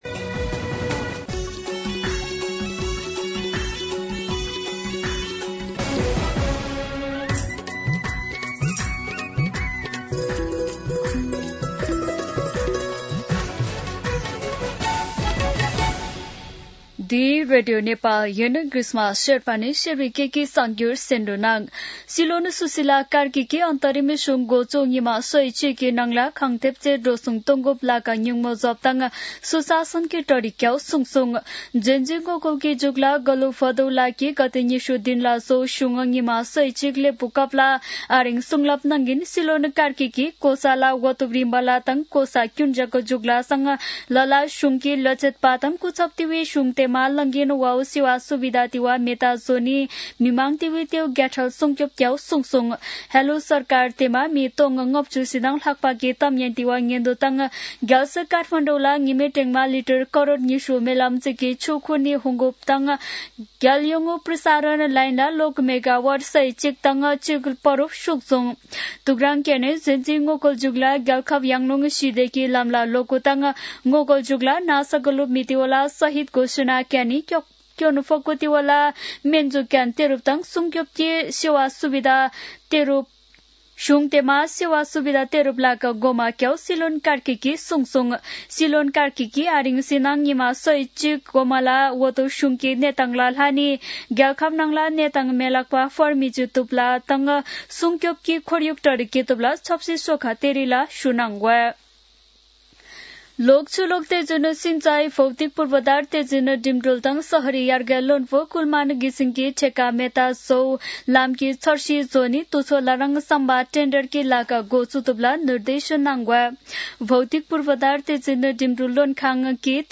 शेर्पा भाषाको समाचार : ५ पुष , २०८२
Sherpa-News-09-5.mp3